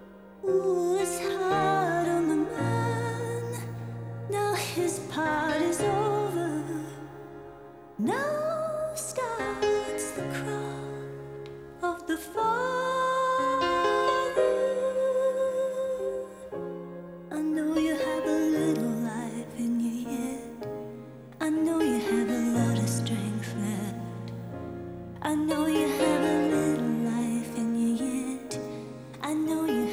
• Adult Alternative